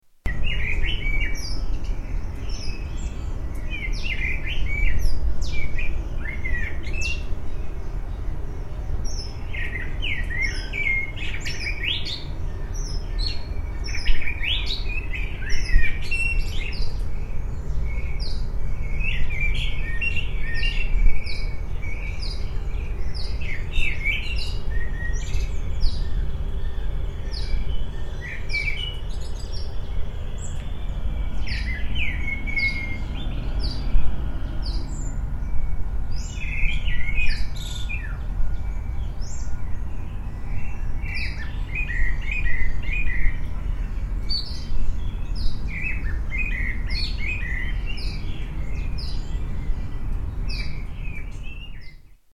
Bird calls at Adelaide